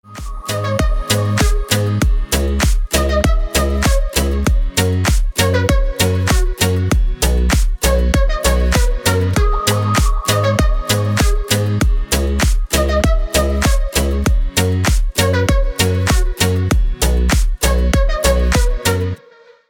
• Качество: 320, Stereo
deep house
без слов
Dance Pop
Cover